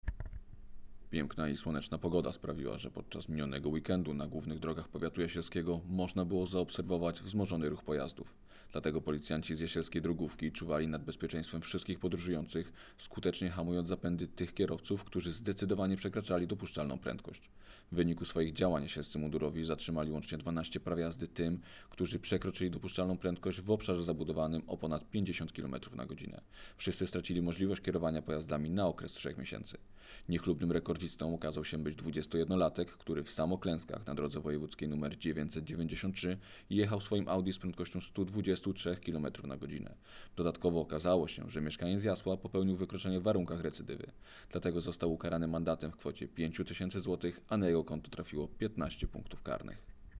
Nagranie audio 12 praw jazdy oraz 123 kmh w warunkach recydywy - mówi